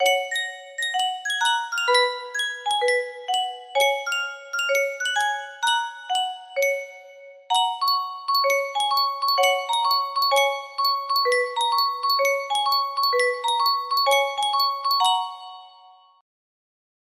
Yunsheng Music Box - Unknown Tune 2428 music box melody
Full range 60